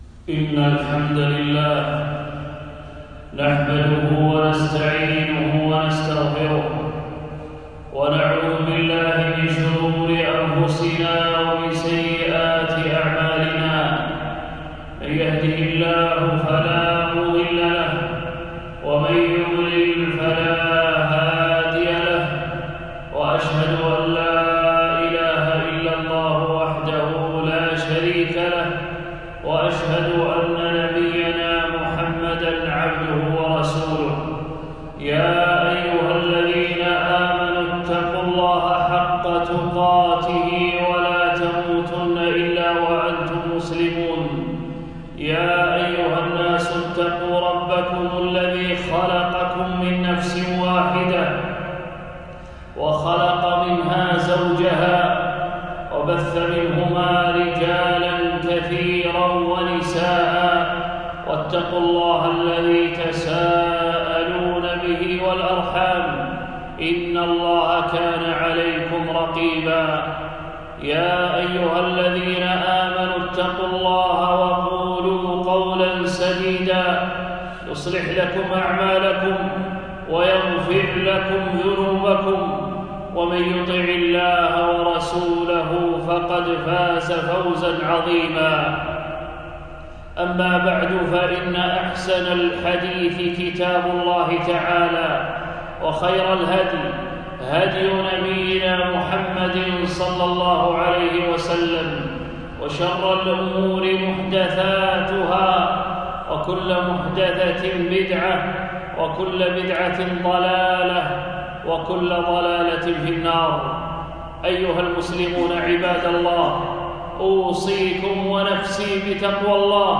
خطبة - أهمية التوحيد وخطورة الشرك